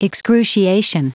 Prononciation du mot excruciation en anglais (fichier audio)
Prononciation du mot : excruciation